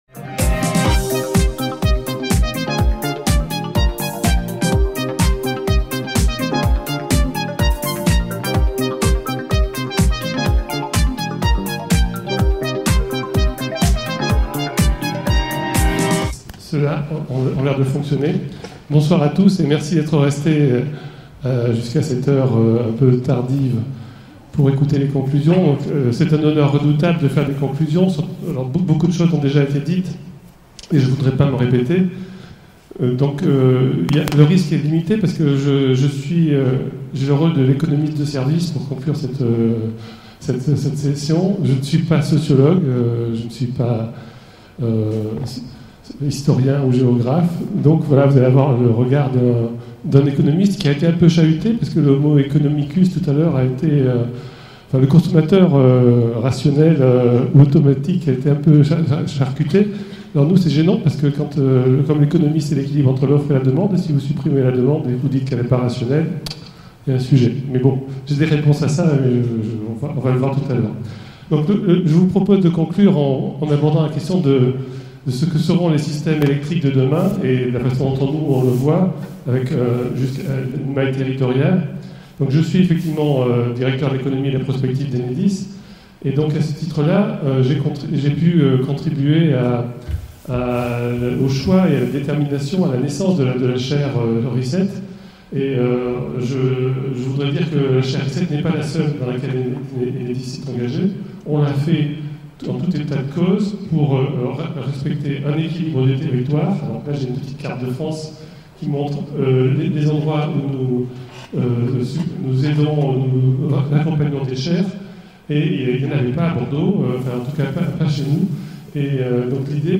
Le 10 avril 2019, la Chaire RESET fête son deuxième anniversaire en organisant une journée d'étude sur les Mutations des systèmes électriques entre perspective sociétale et coopération(s) "territoriale(s)".